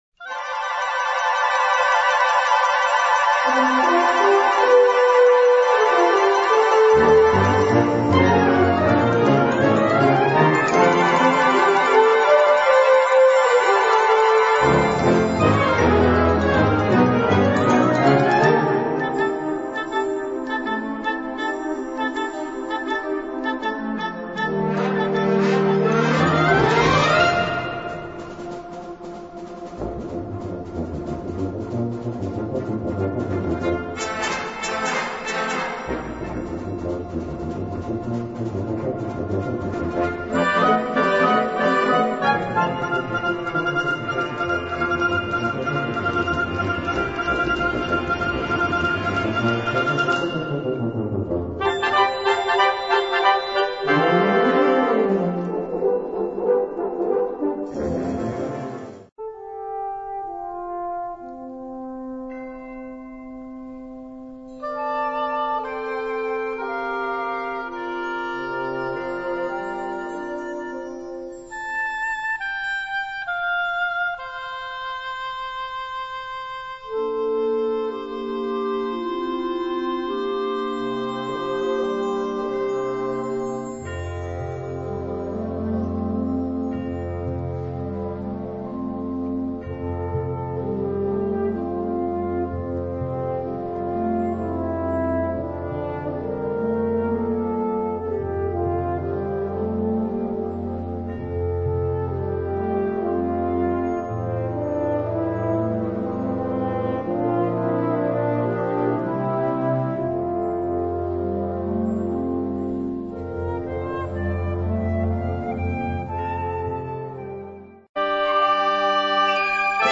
Unterkategorie Zeitgenössische Bläsermusik (1945-heute)
Besetzung Ha (Blasorchester)